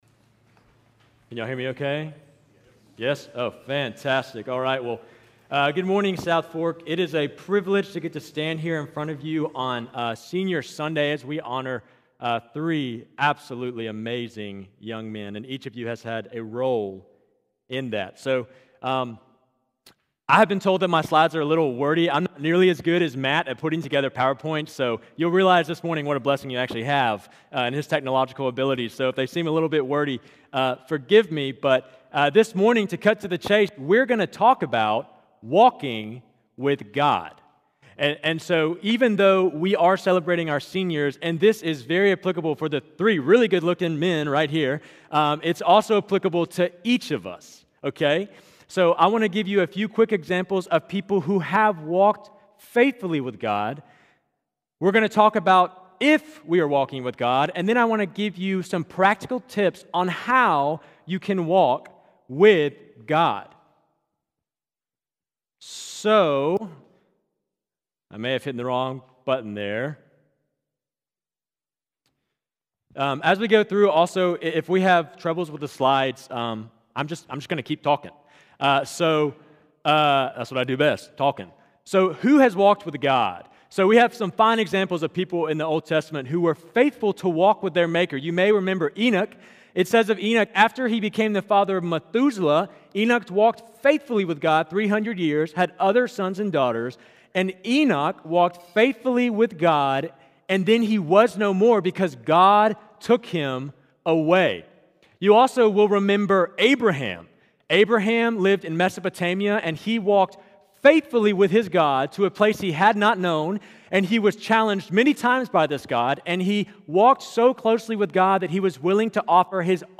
Sermon Audio – walking